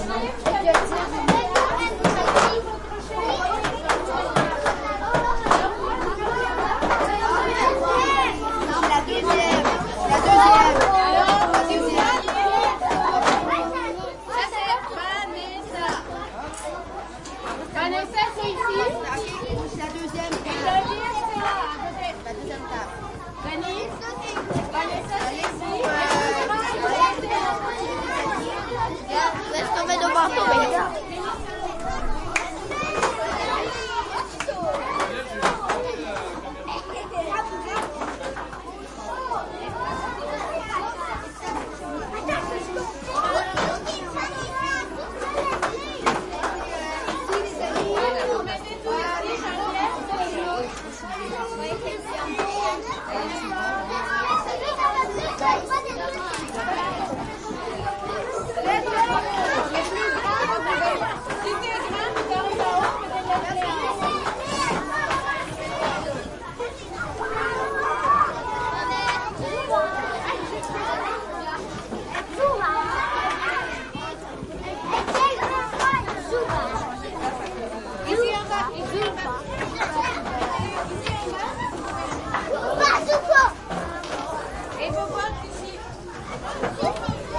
蒙特利尔 " 人群中 小学或幼儿园的孩子们 法语儿童 魁北克午餐时间 孩子们活泼好动的游戏1
描述：人群int小学或幼儿园儿童法国enfants quebecois午餐时间孩子活泼的活动games1
Tag: 儿童 儿童组织 魁北克 小学 活泼 好动 幼儿园 法国 INT 人群 孩子们 学校 沃拉